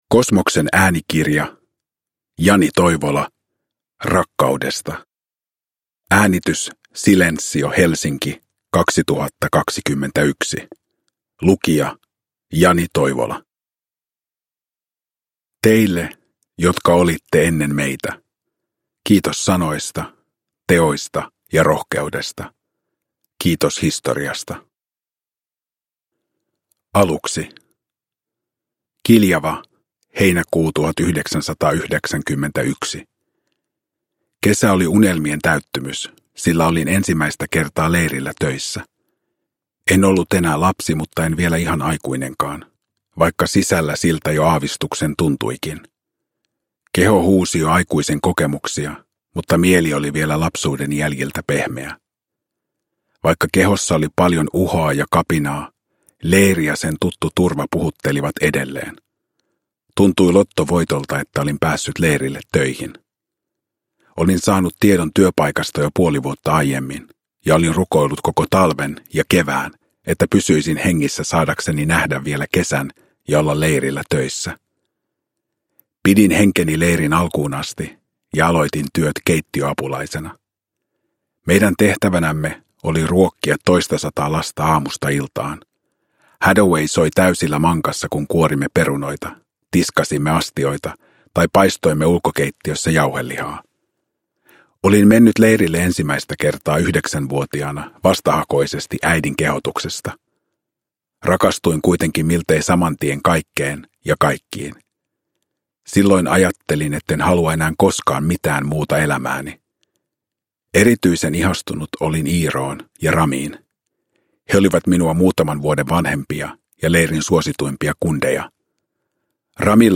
Uppläsare: Jani Toivola